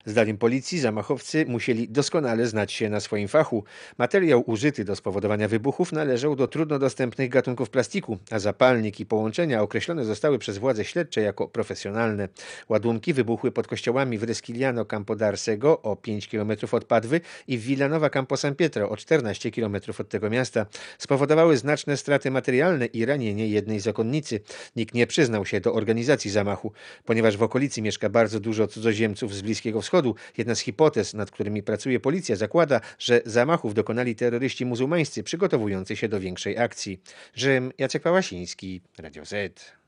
*Posłuchaj relacji korespondenta Radia Zet (1.2 MB)*